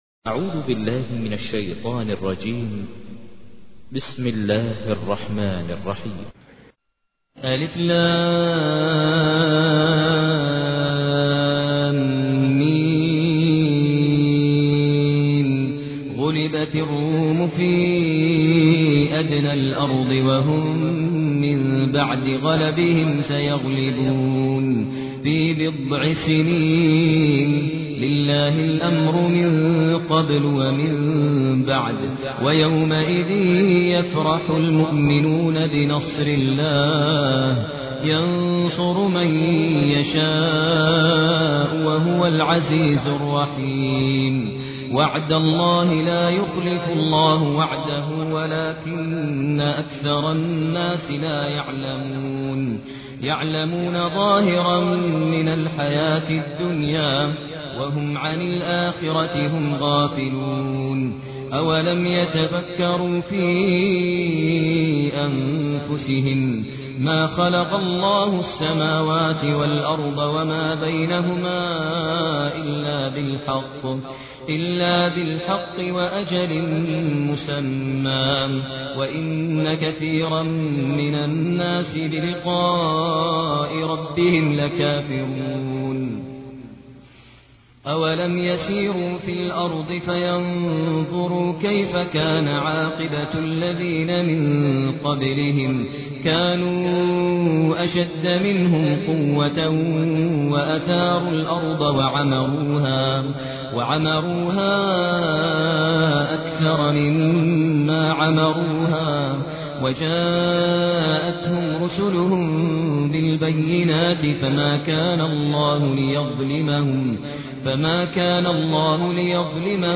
تحميل : 30. سورة الروم / القارئ ماهر المعيقلي / القرآن الكريم / موقع يا حسين